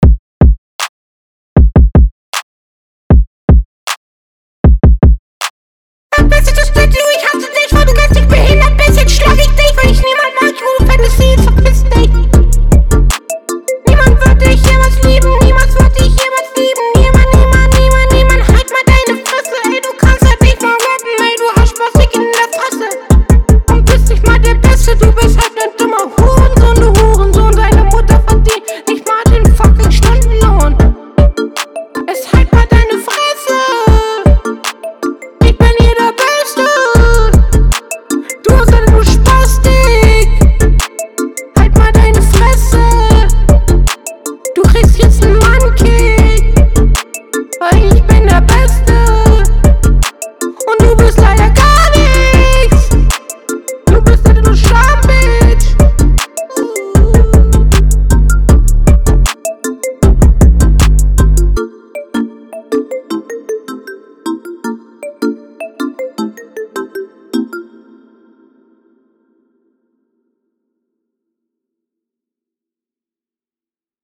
Der Beat ist fantastisch.
autsch. der autotune geht garnicht bruder, also is echt kein hörgenuss aber man verstehts schon …